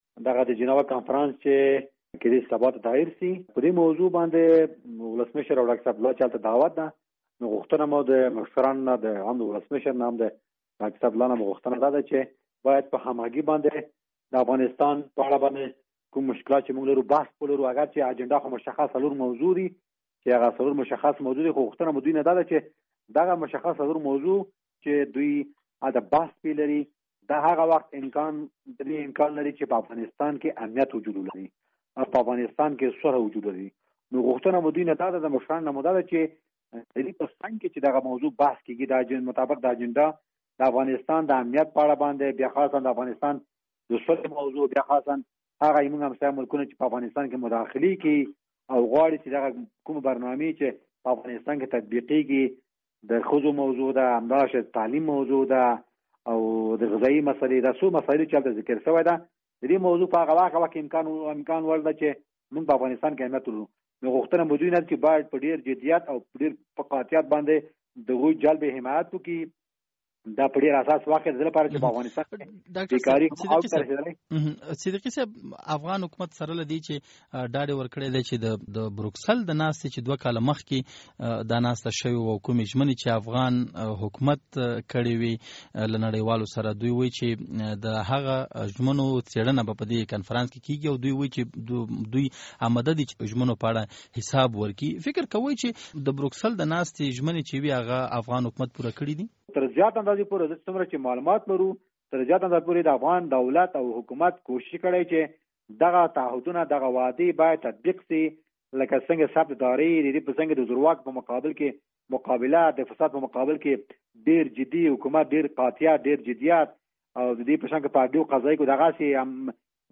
مرکه
له اصف صدیقي سره مرکه